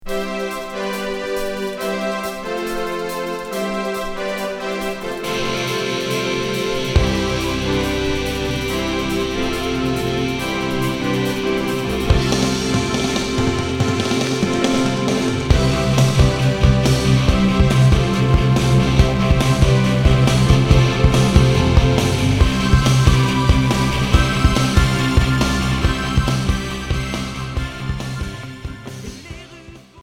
Hard FM